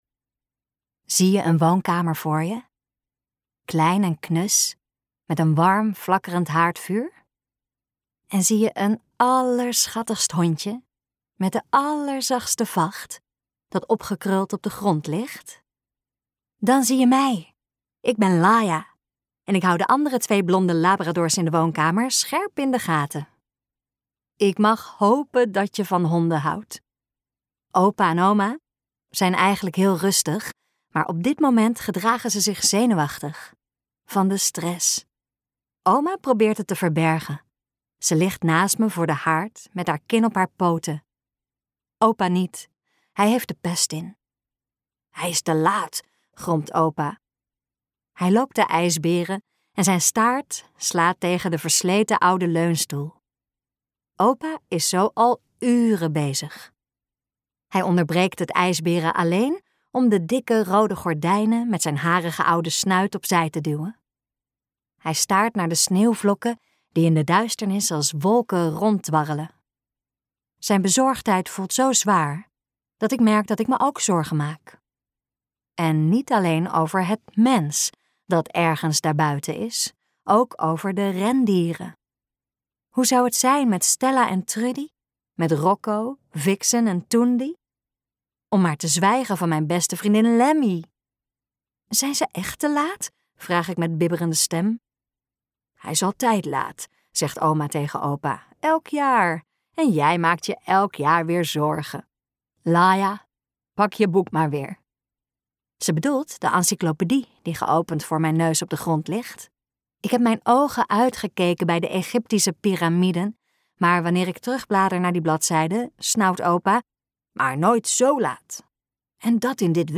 Uitgeverij De Fontein | Het allerlaatste kerstcadeau luisterboek